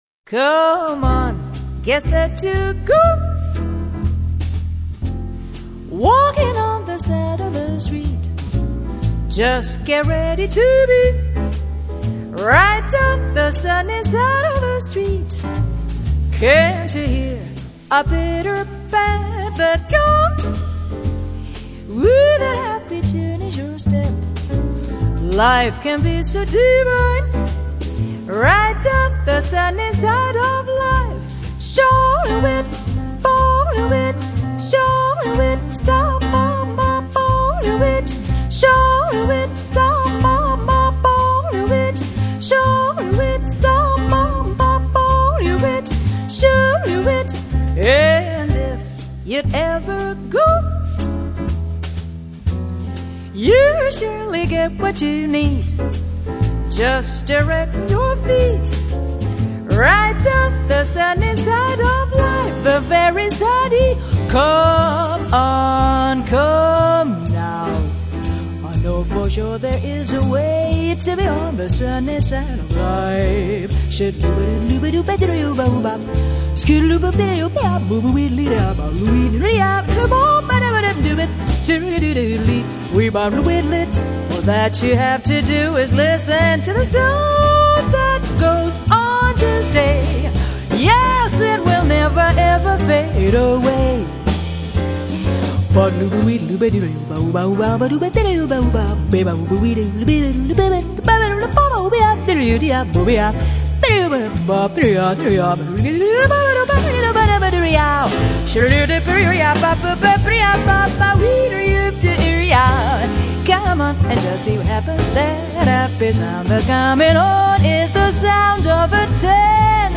et aussi la qualité d'accompagnement tout en finesse.